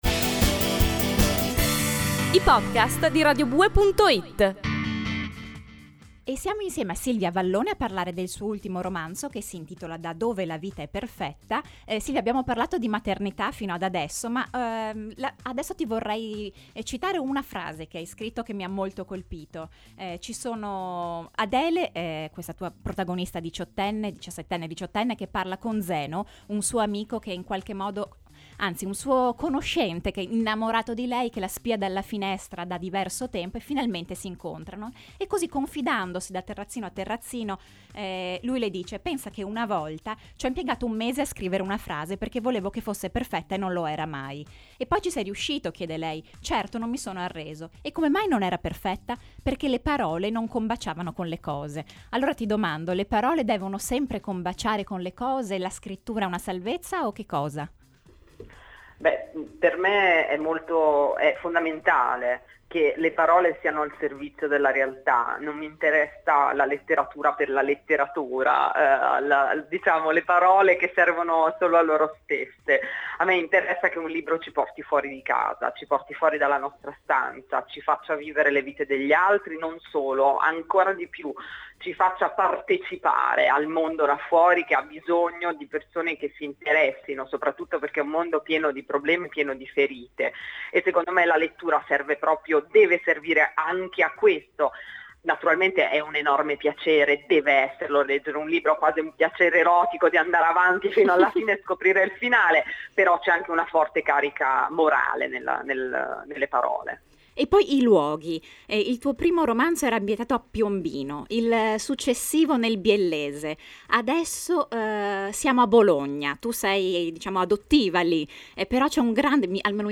Da dove la vita è perfetta, intervista a Silvia Avallone
Intervista-a-Silvia-Avallone-parte-2.mp3